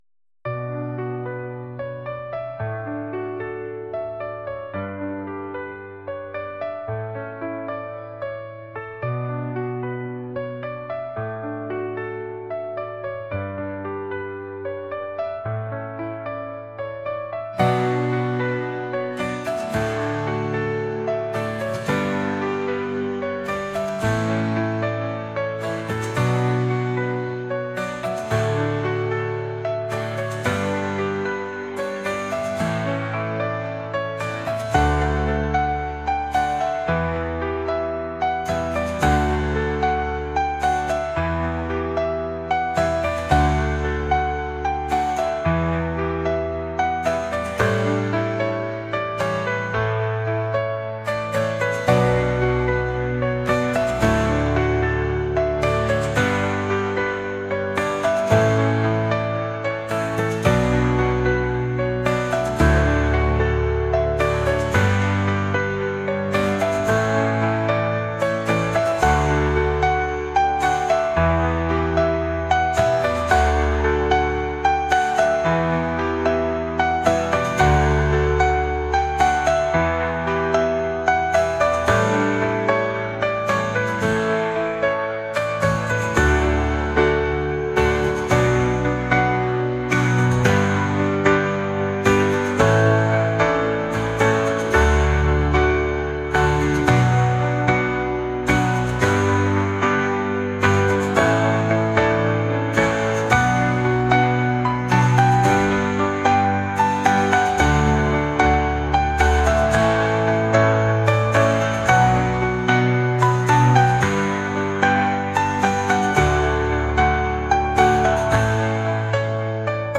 ambient | pop | indie